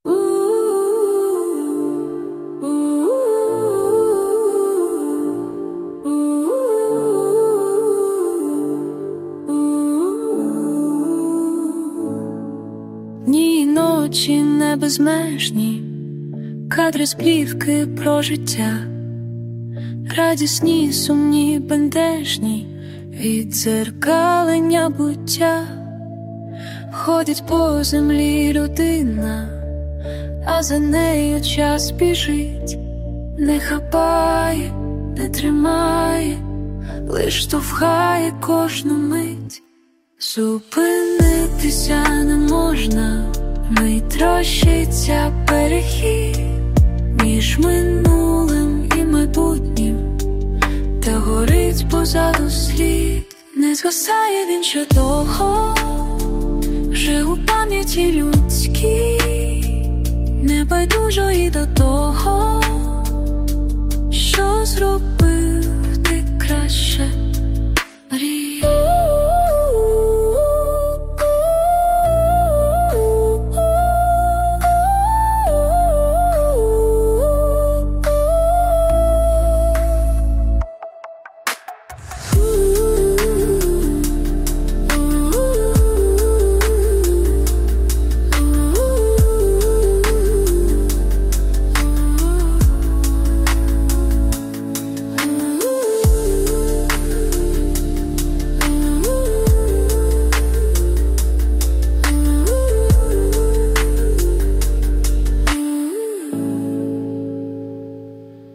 Текст - автора, мелодія і виконання - штучно сгенеровані
ТИП: Пісня
СТИЛЬОВІ ЖАНРИ: Ліричний